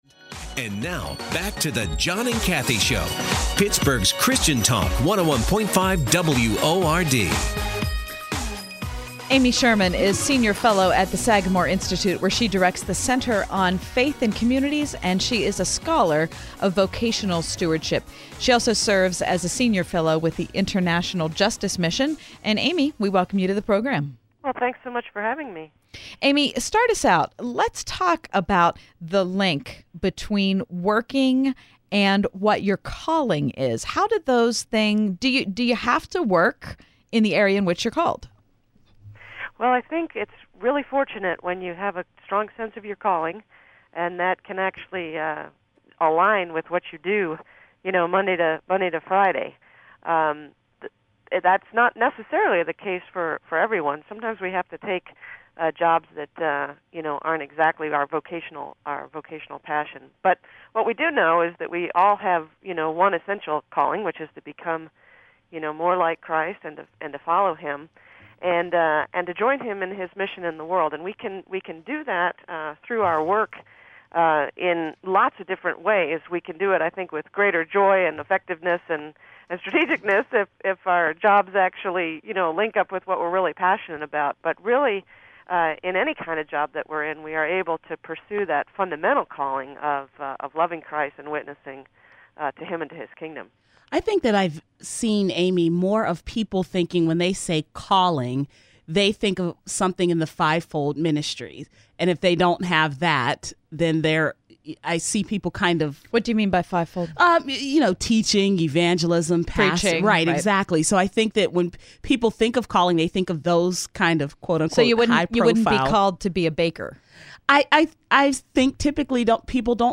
Interviews with the Author